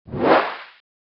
ob-sfx-mosaic-out.a0b5f188d0eef0a8ca71.mp3